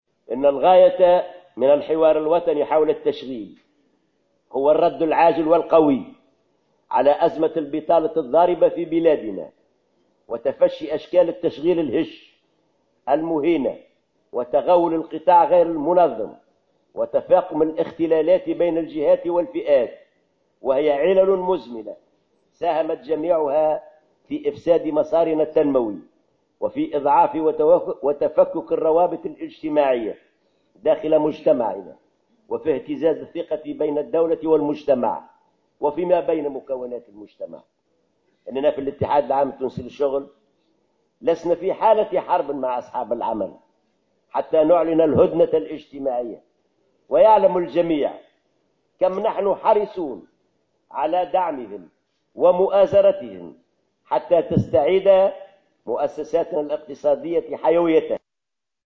وأكد العباسي خلال الجلسة الختامية للمرحلة الأولى من الحوار الوطني حول التشغيل أن الاتحاد ليس في حالة حرب مع أصحاب العمل حتى يعلن الهدنة الاجتماعية، مشيرا إلى أن المنظمة الشغيلة حريصة على دعم المؤسسات الاقتصادية حتى تستعيد عافيتها لكن مع التصدي لأشكال التشغيل الهش المهينة وتغول القطاع غير المنظم، وفق تعبيره.